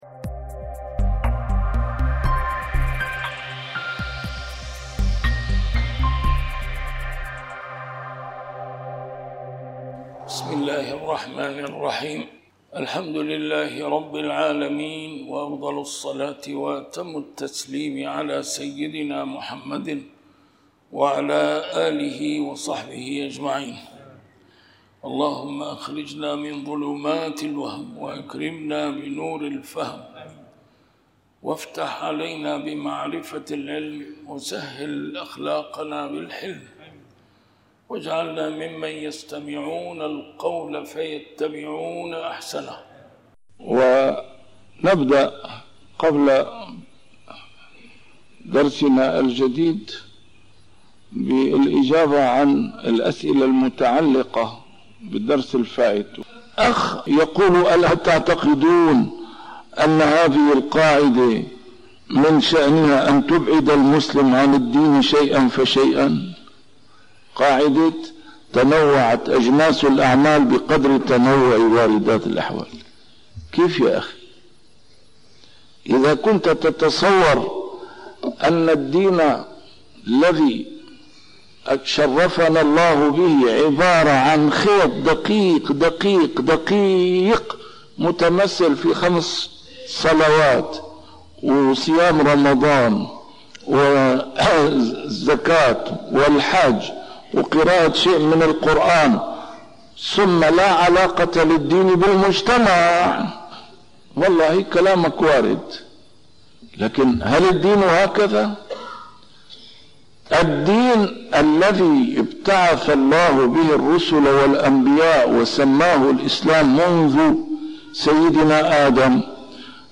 A MARTYR SCHOLAR: IMAM MUHAMMAD SAEED RAMADAN AL-BOUTI - الدروس العلمية - شرح الحكم العطائية - الدرس رقم 18 شرح الحكمة 10